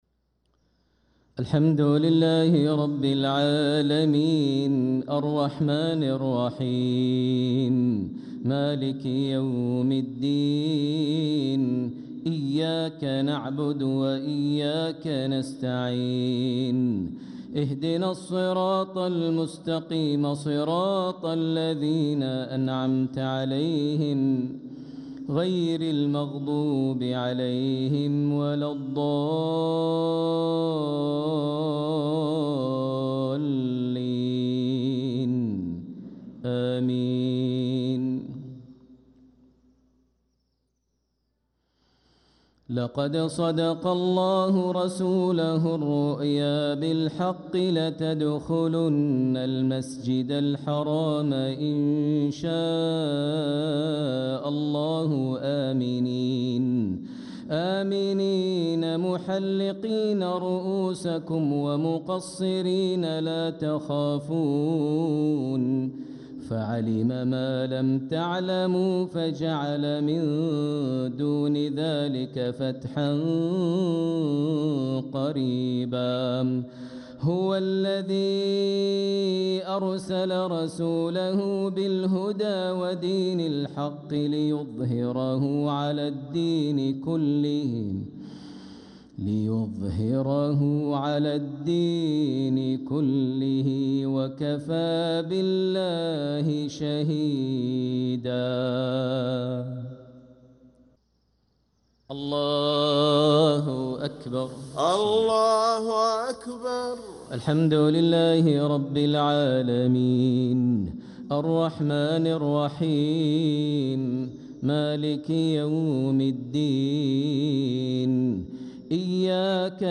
صلاة المغرب للقارئ ماهر المعيقلي 27 جمادي الأول 1446 هـ
تِلَاوَات الْحَرَمَيْن .